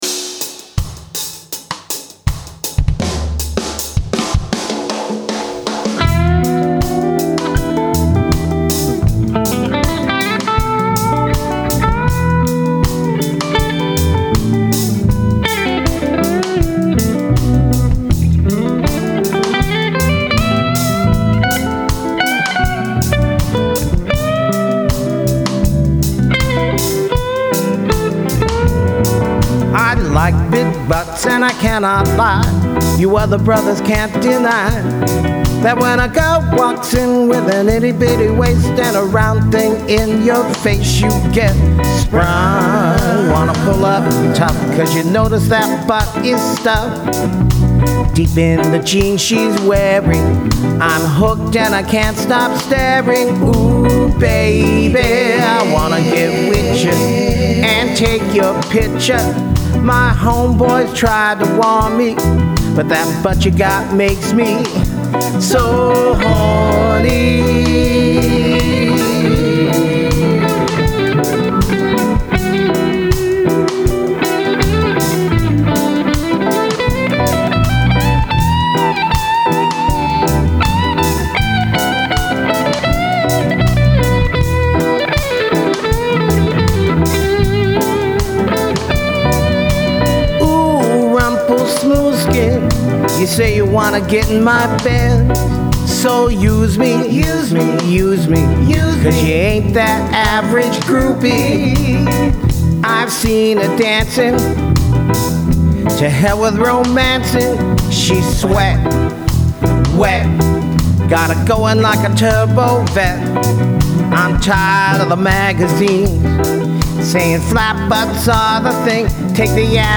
Amp: Aracom VRX18 clean channel